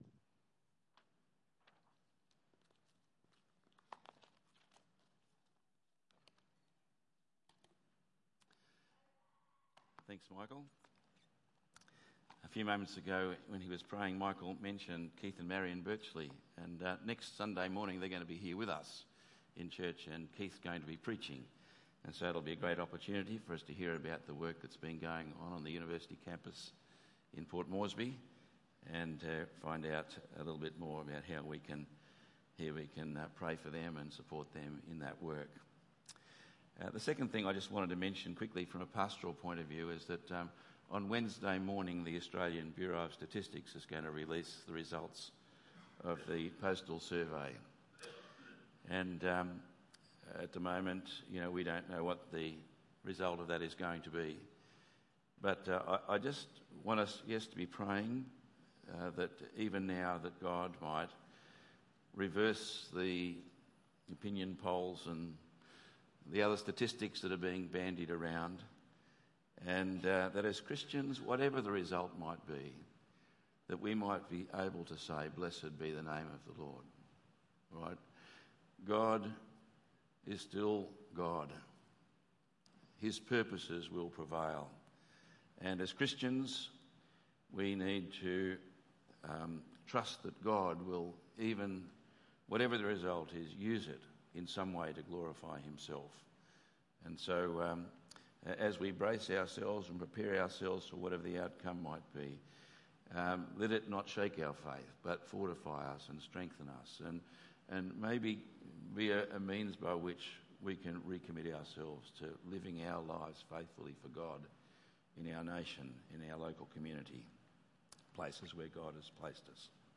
Numbers 22:1-24:25 Tagged with Sunday Morning